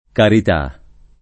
carità [ karit #+ ] s. f.